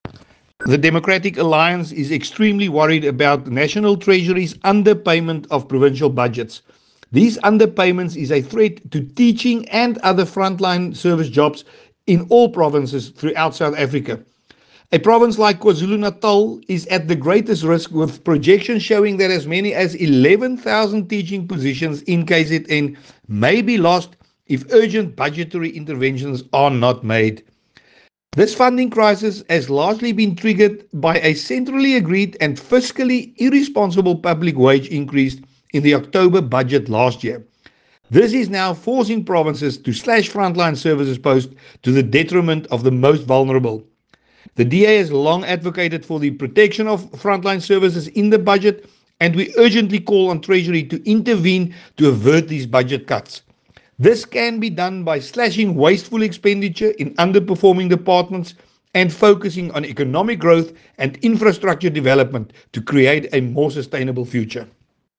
Issued by Willie Aucamp MP – DA National Spokesperson
Note to editors: Please find attached soundbites in